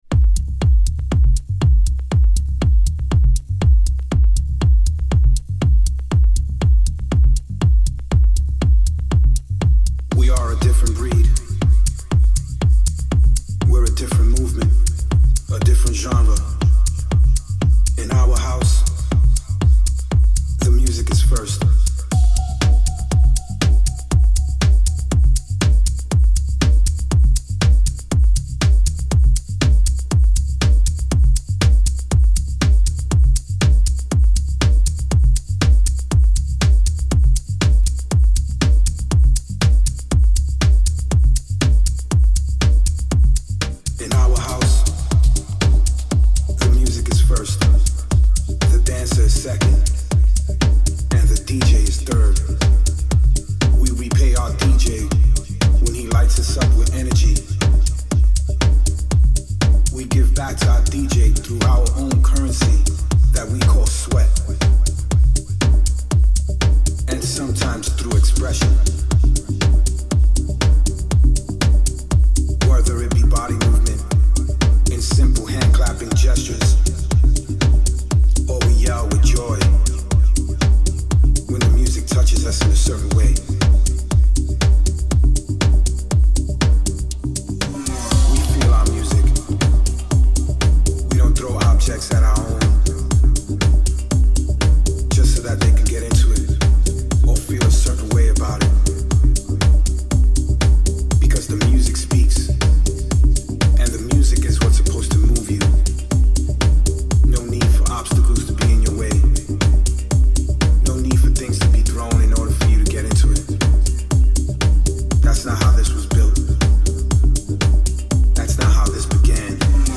今回のテストにはCondesa Carmen V (100V-240V対応) を使用しました。
副作用として音のバランスが変わり、少し不安定さが増しています。少し歪み感も増しているような、いないような。
昇圧トランスを使用すると明瞭度が増し、推進力がアップしました。